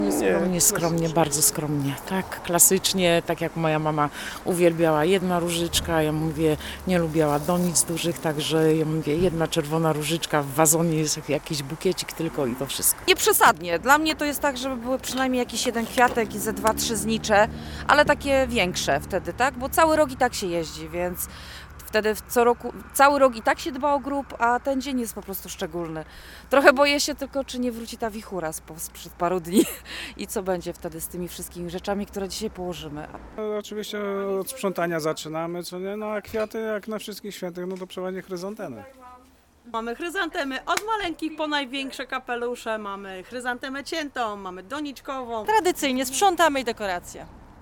Na zielonogórskiej nekropolii trwają porządki. Zapytaliśmy mieszkańców jak przygotowują groby najbliższych do tej uroczystości?